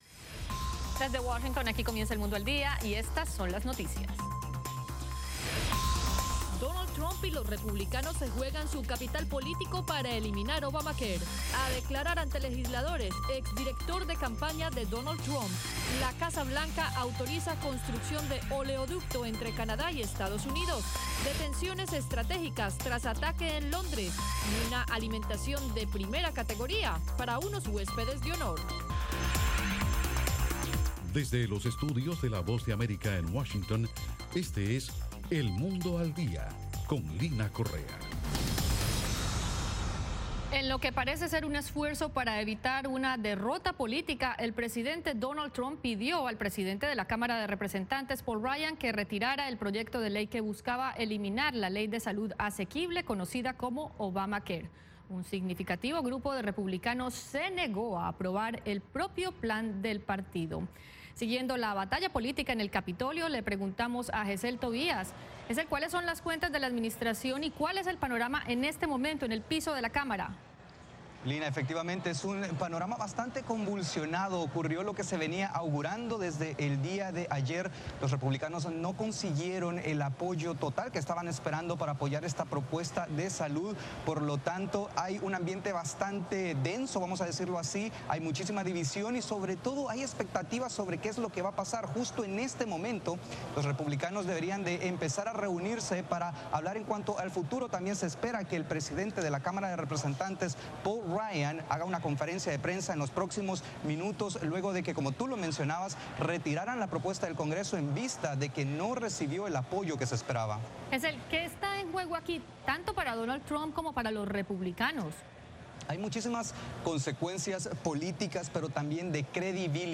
Diez minutos con las noticias más relevantes del día, ocurridas en Estados Unidos y el resto del mundo.